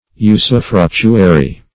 usufructuary - definition of usufructuary - synonyms, pronunciation, spelling from Free Dictionary
Usufructuary \U`su*fruc"tu*a*ry\, n. [L. usufructuarius.] (Law)